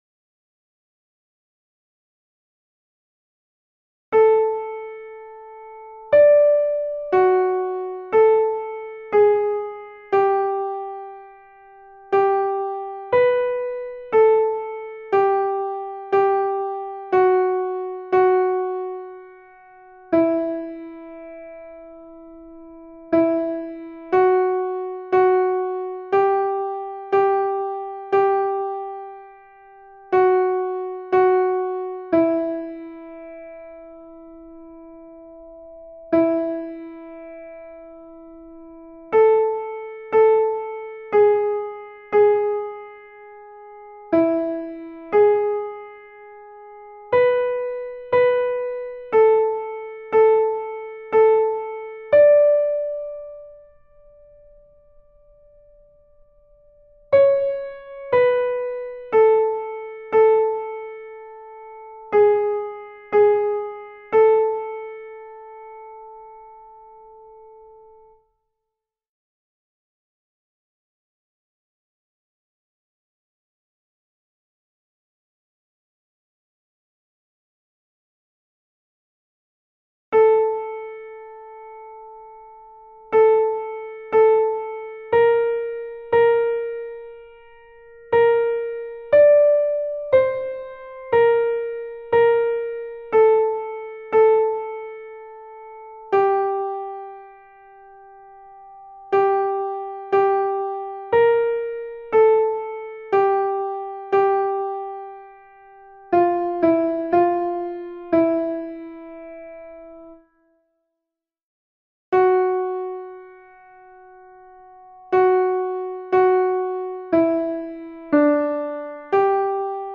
MP3 versions chantées
Soprano (piano)
Ave Verum Corpus Mozart Soprano Mp 3